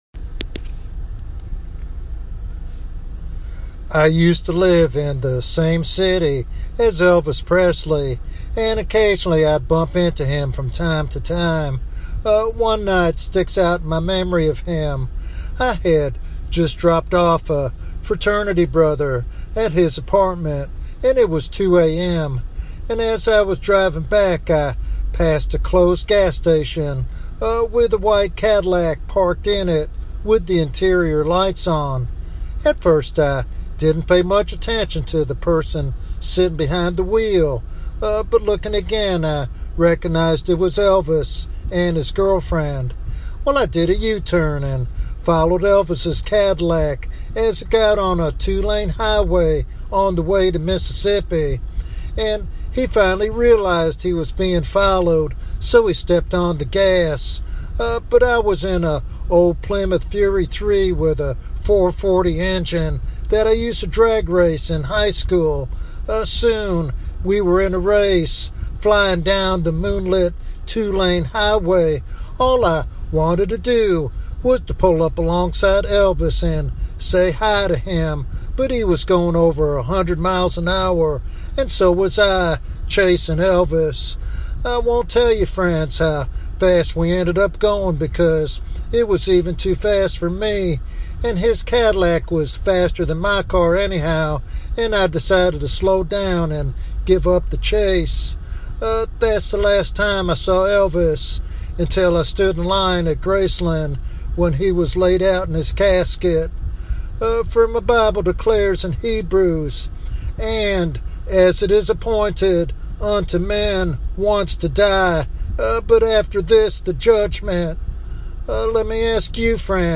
This evangelistic sermon urges believers and seekers alike to prioritize their spiritual destiny over temporal gains.
Sermon Outline